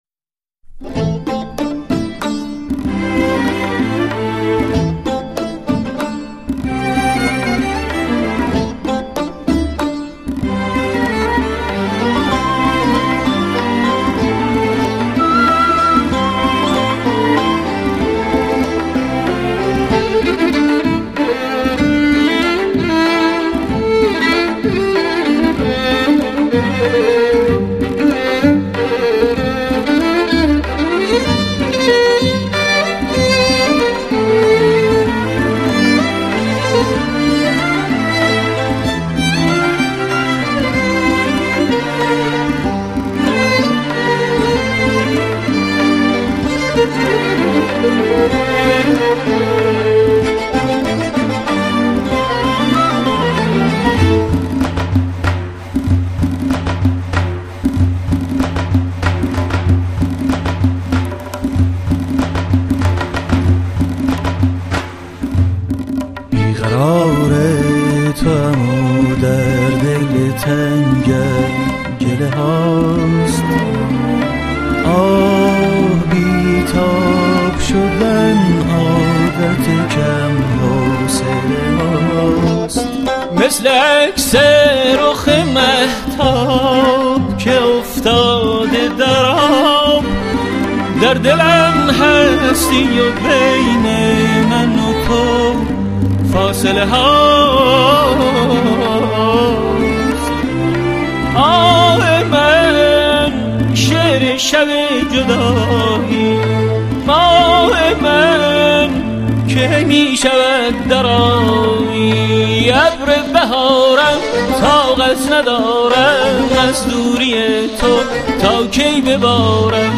** موسيقي ملّي و سنتي **